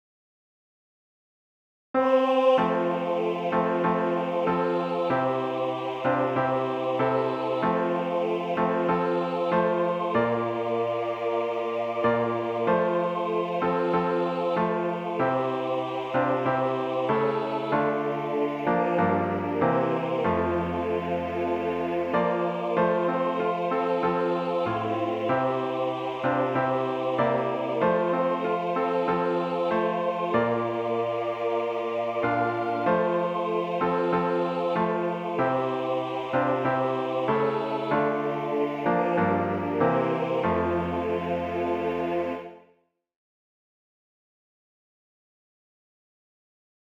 Instrumentation: Concert Band, SATB Chorus
Ensemble: Band/Wind Ensemble, SATB Chorus
Synth recording: